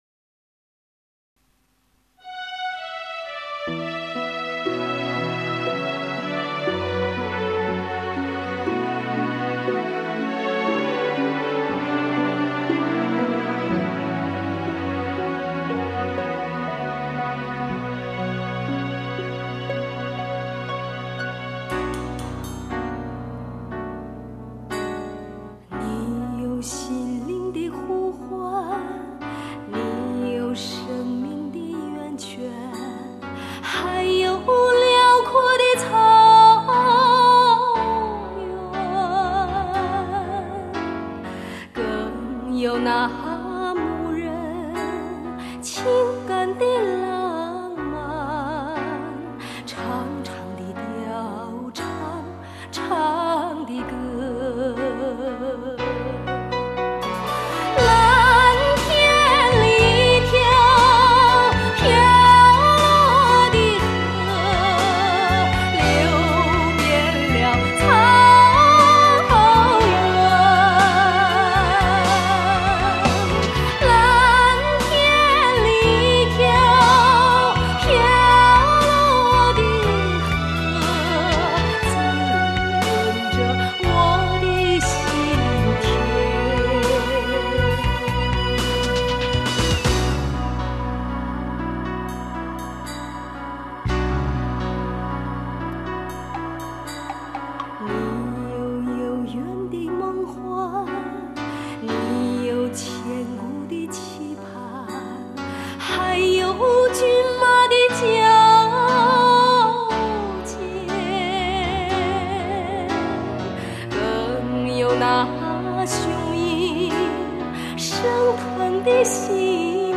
蒙古长调